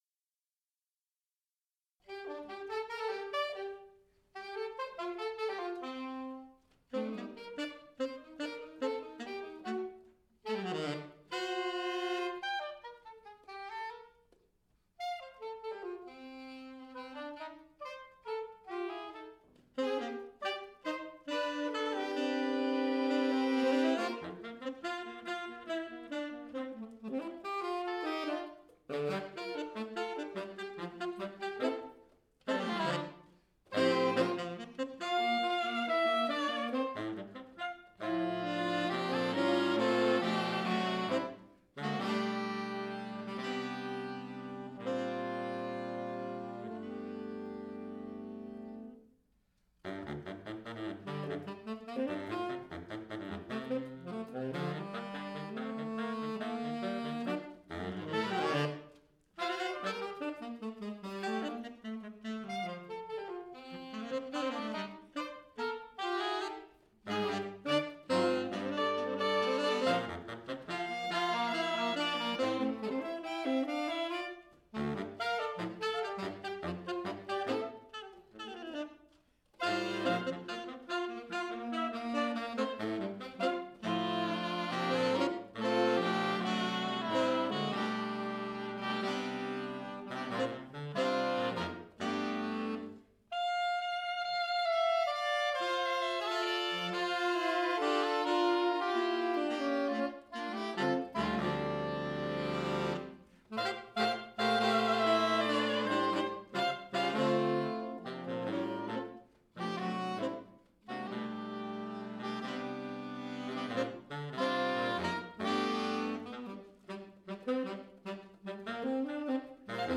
Voicing: 4 Sax